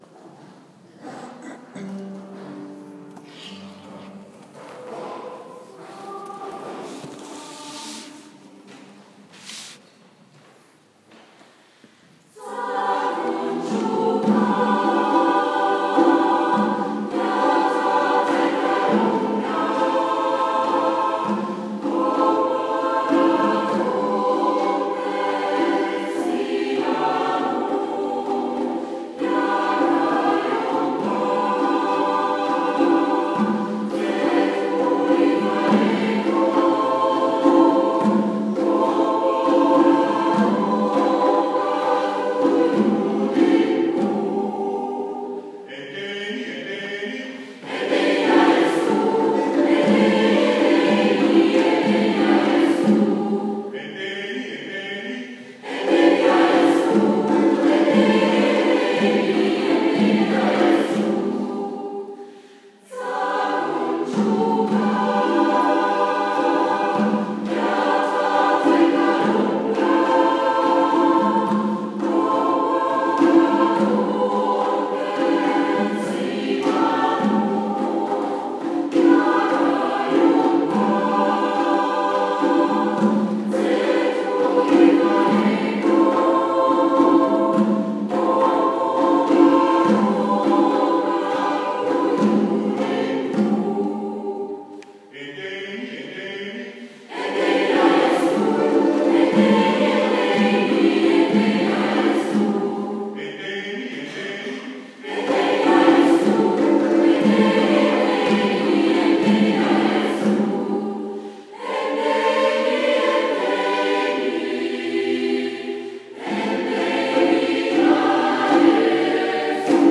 Zahlreiche Frauen und auch einige Männer verschönern mit ihrem Gesang nicht nur den wöchentlichen Sonntagsgottesdienst, sondern auch viele kirchliche Feste und Feiern.